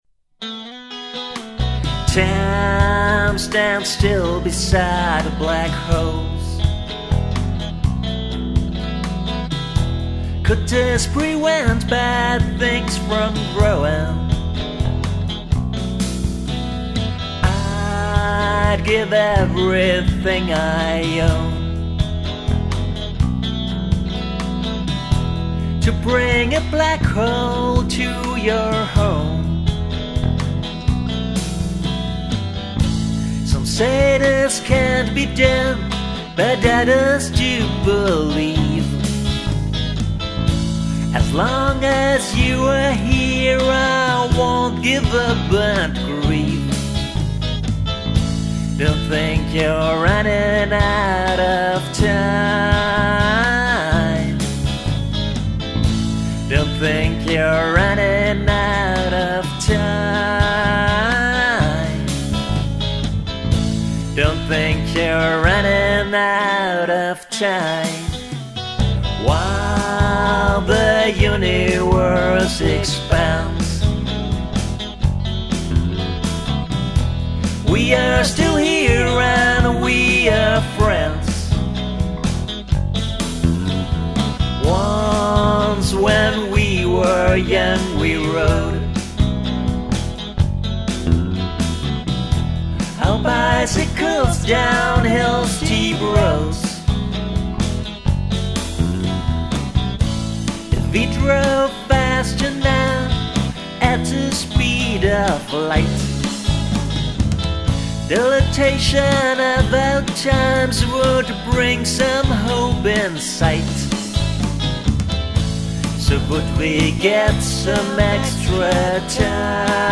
vocals, electric guitars, bass, drum-programming